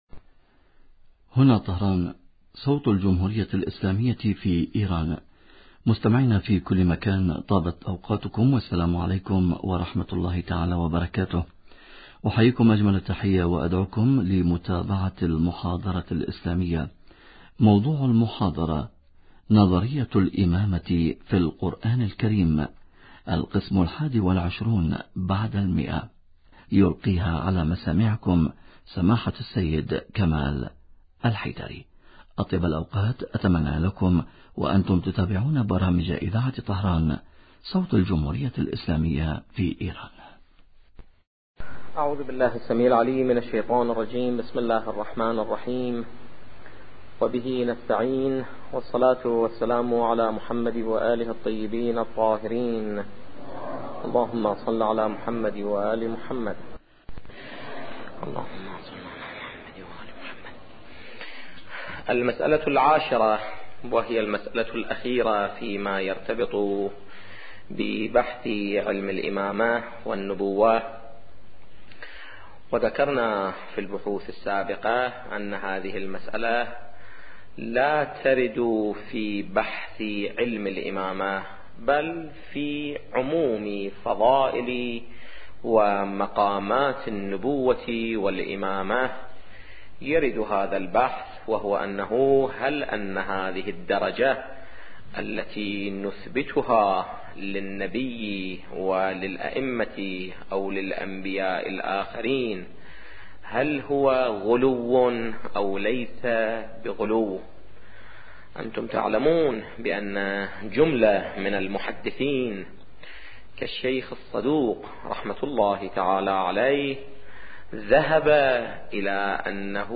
نظرية الامامة في القران الكريم - الدرس الحادي والعشرون بعد المئة